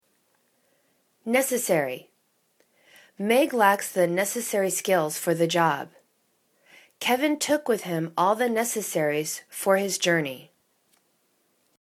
ne.ces.sa.ry /'nesiseri/ adj. n.